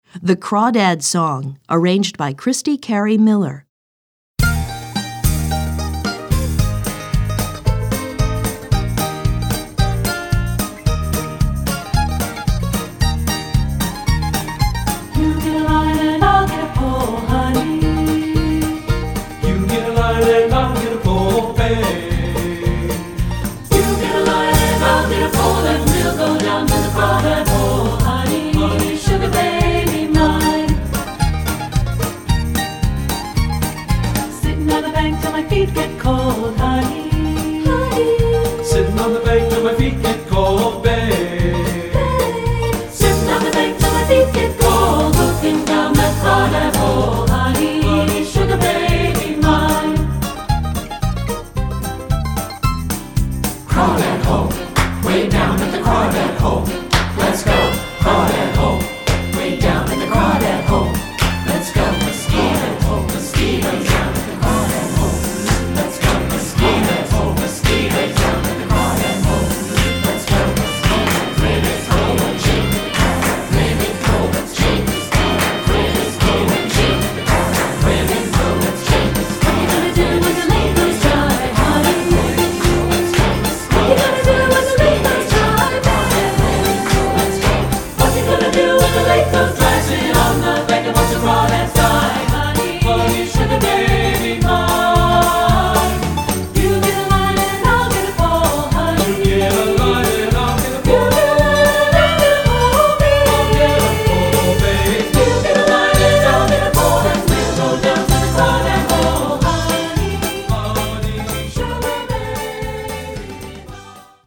3 Part Mix
General Music Elementary Choral & Vocal Concert Choral Folk
American Folk Song